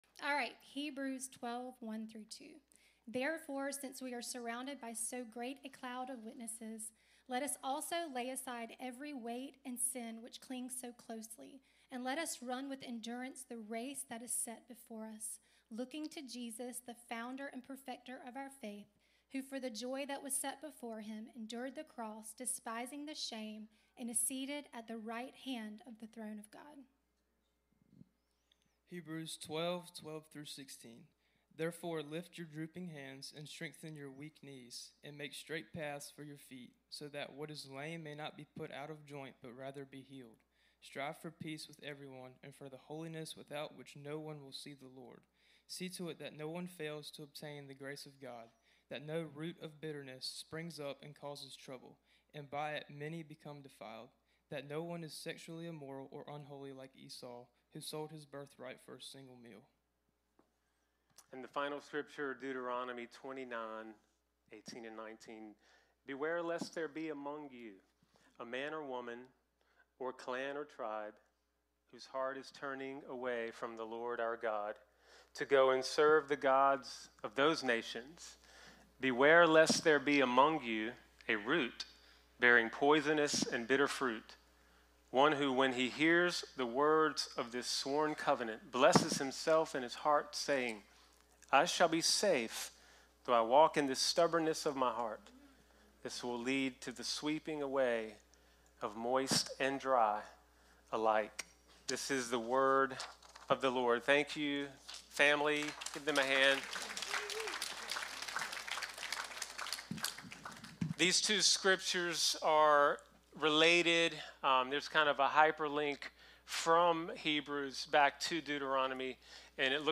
Series: Rooted Service Type: Sunday 10am